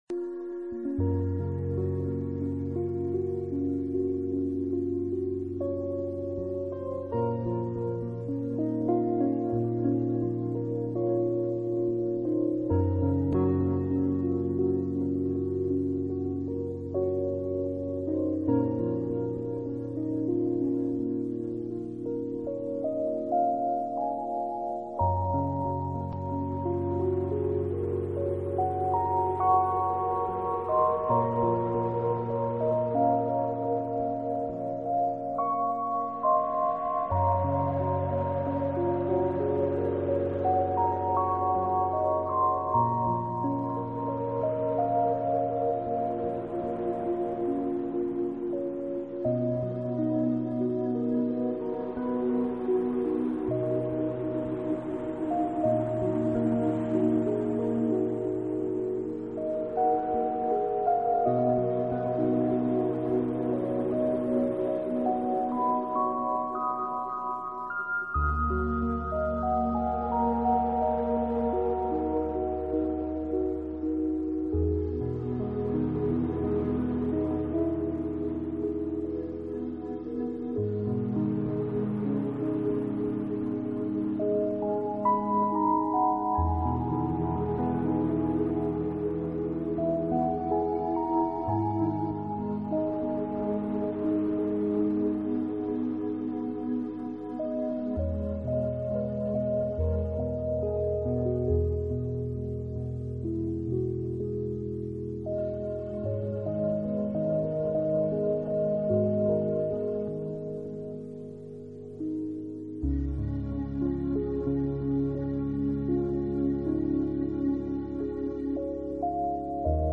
新世纪纯音乐】环境音乐大师